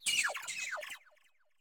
Cri de Taupikeau dans Pokémon Écarlate et Violet.